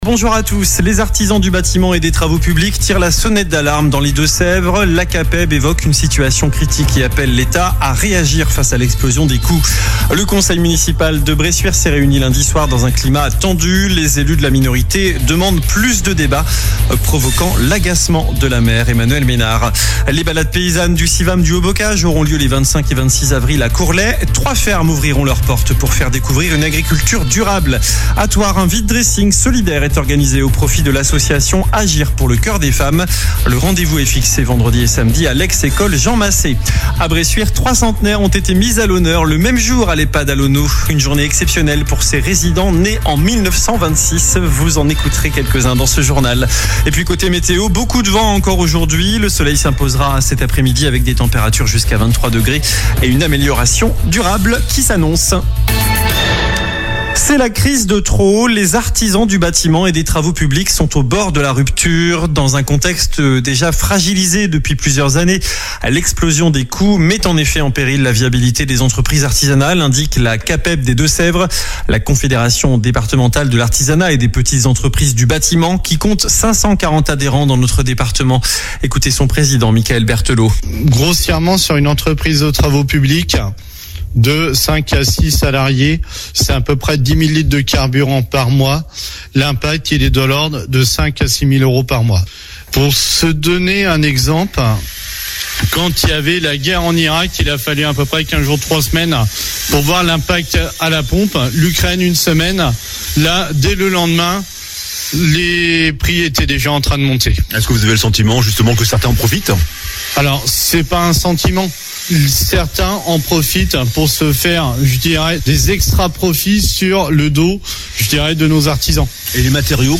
infos Deux-Sèvres